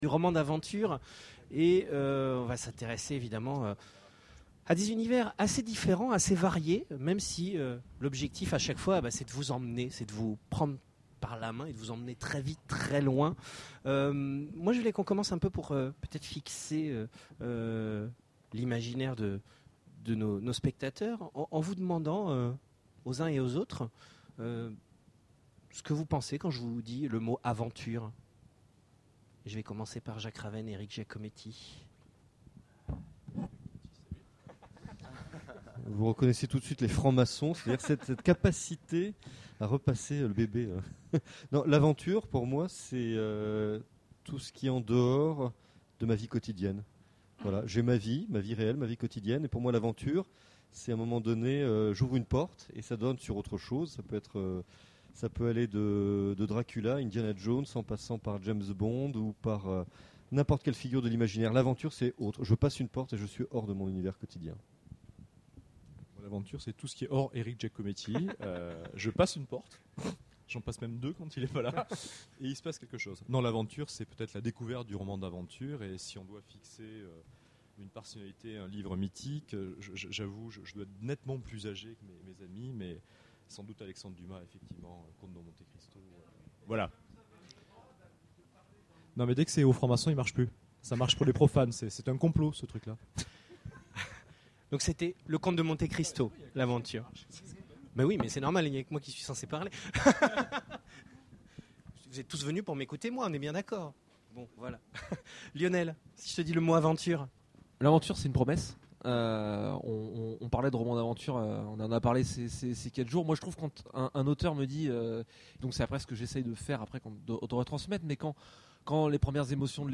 Imaginales 2013 : Conférence Des romans d'aventure...